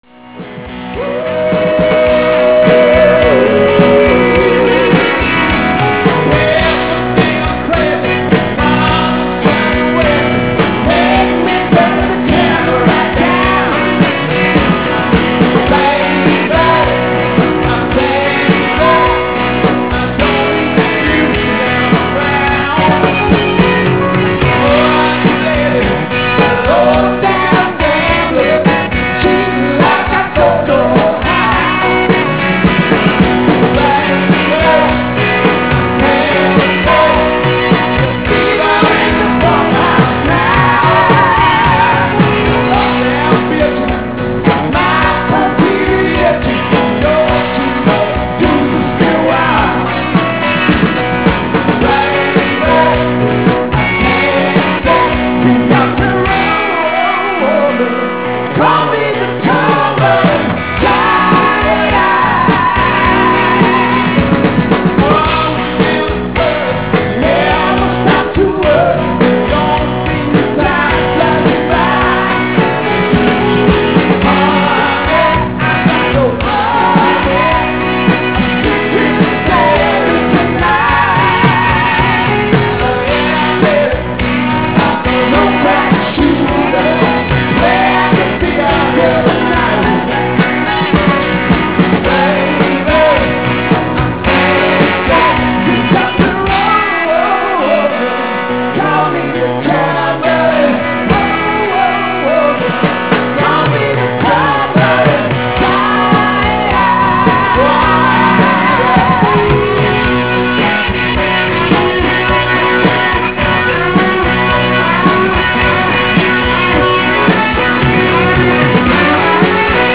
Live Audio Clips
Toronto Aug. 20th